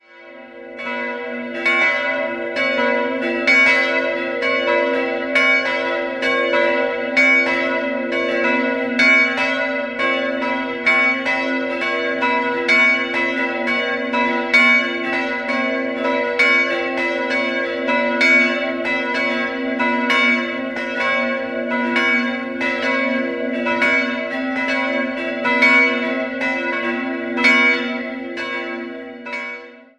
Die ehemalige, 1772 vollendete Schlosskapelle ist heute Filialkirche. 3-stimmiges Gloria-Geläut: h'-cis''-e'' Die kleine Glocke ist historisch, die beiden größeren wurden 1989 von der Gießerei Bachert in Bad Friedrichshall gegossen.